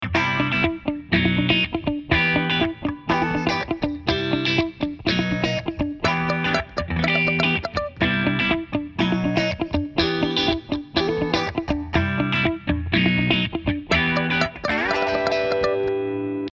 2つのギターを両サイドに配置することで、ステレオの広がりが出て立体的な印象になります。
▶ギターを左右に配置した結果